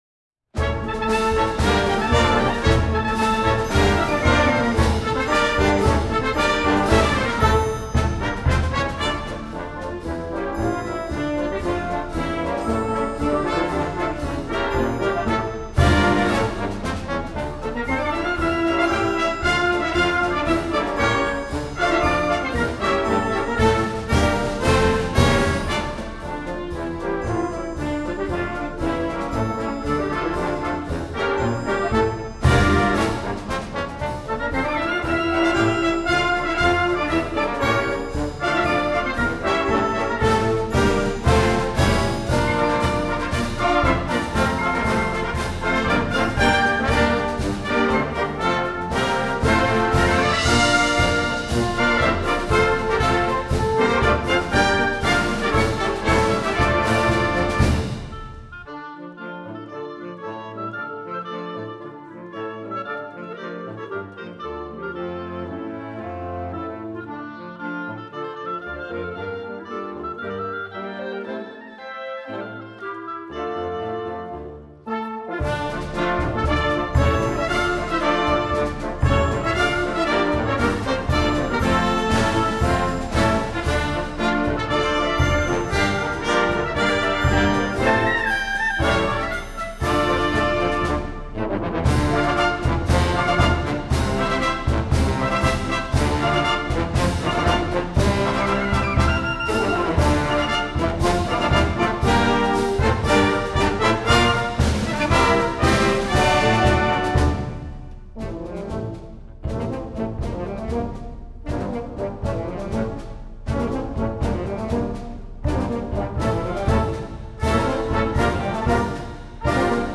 Spanish March